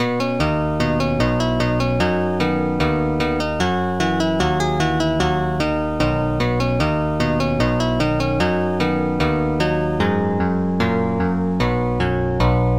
GuitarNote